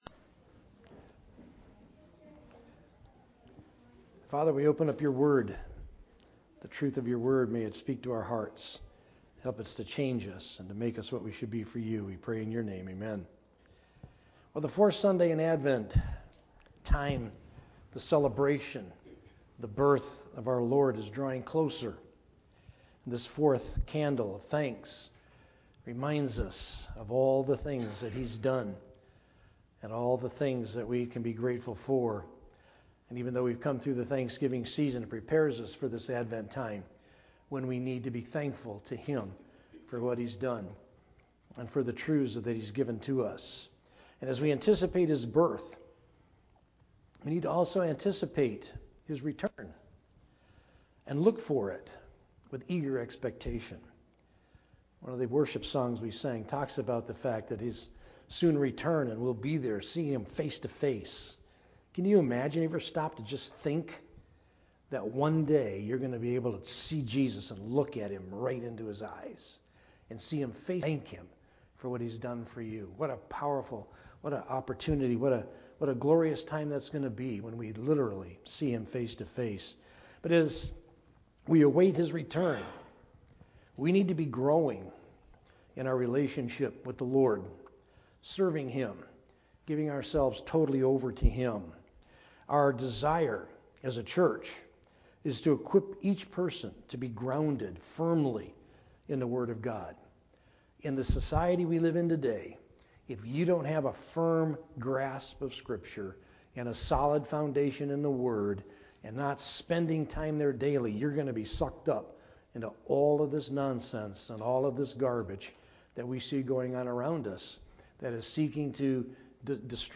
From Series: "Sunday Morning - 11:00"
Related Topics: Sermon